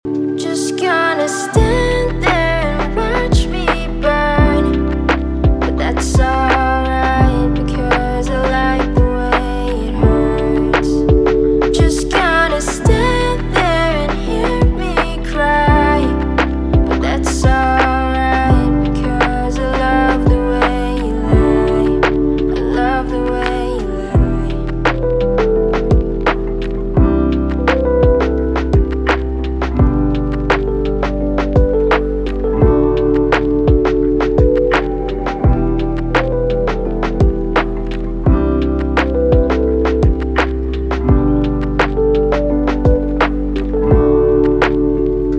Жанр: Инди